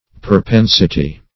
Perpensity \Per*pen"si*ty\, n.
perpensity.mp3